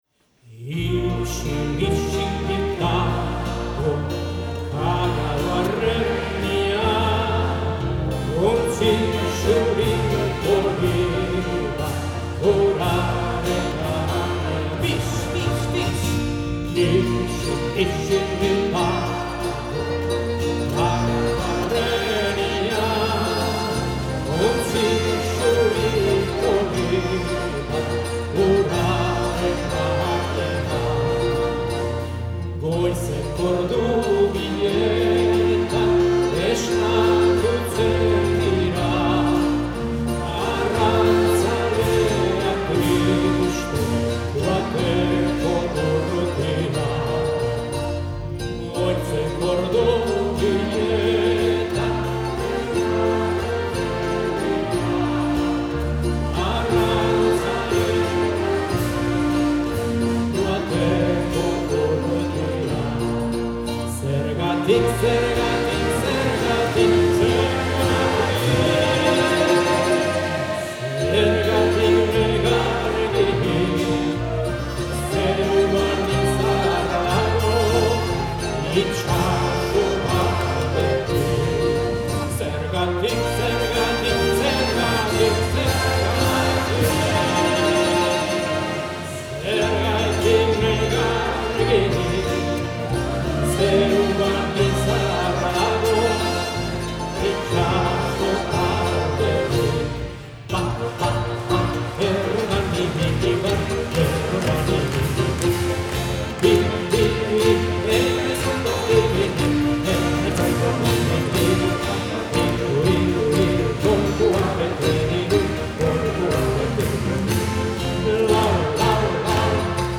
Musikeneren inguruan sortutako Wachisneis taldeak izan zuen jazz-kontzertuaren ardura, eta Errenteriako Musika Kulturaren Elkarteko Musika Banda, berriz, euskarri ezin hobea izan zen Oskorri talde ezagunaren emanaldirako.
Oskorri taldea eta Errenteriako Musika Kultur Elkarteko Banda